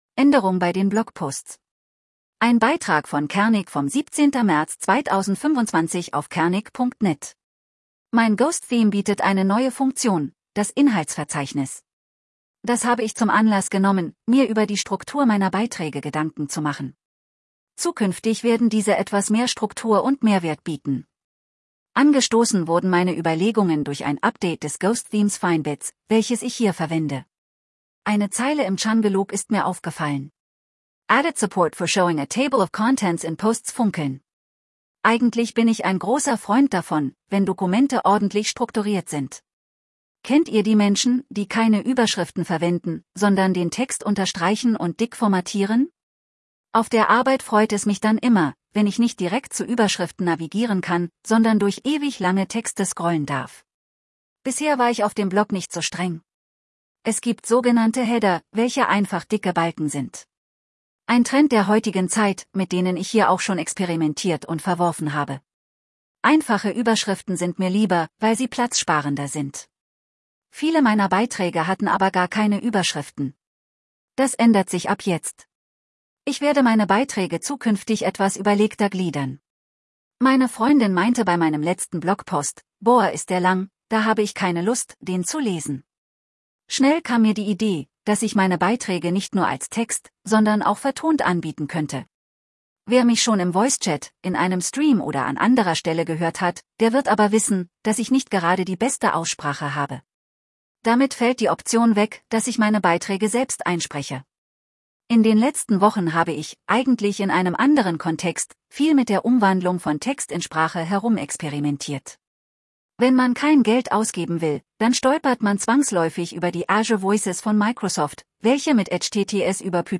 edge-tts –rate=+15% –voice de-DE-KatjaNeural –file input.txt –write-media output.mp3
Schwierig wird es beispielsweise, wenn einzelne englische Worte in einem Satz vorkommen.
Gamer wird einfach nicht gut vorgelesen und ich habe es in der Vorlage für die Sprachumwandlung durch Spieler ersetzt.